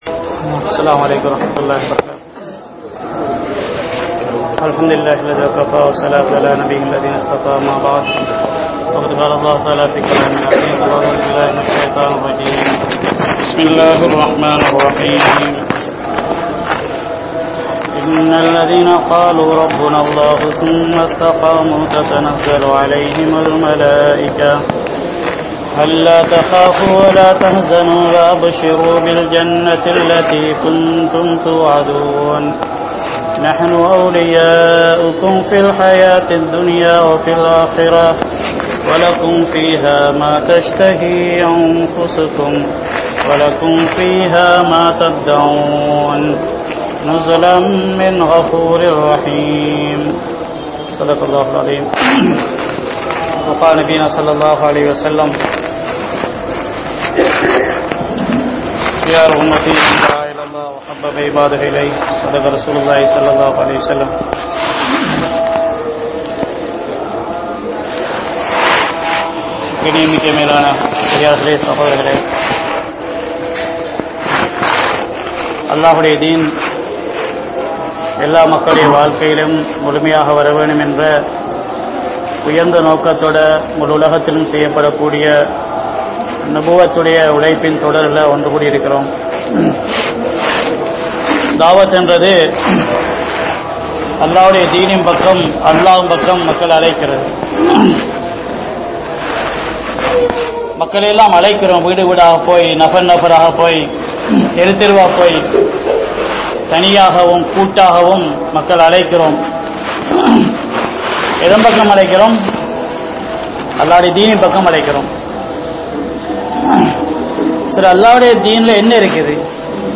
Iruthi Moochchin Mudivu (இறுதி மூச்சின் முடிவு) | Audio Bayans | All Ceylon Muslim Youth Community | Addalaichenai
Safa Jumua Masjidh